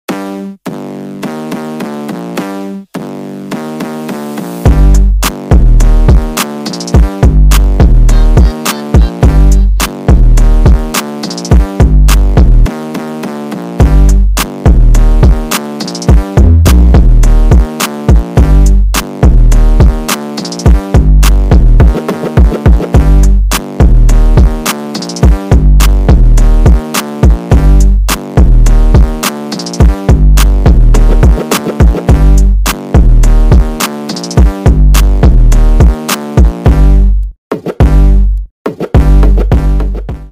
бесплатный рингтон в виде самого яркого фрагмента из песни
Рэп и Хип Хоп
громкие # без слов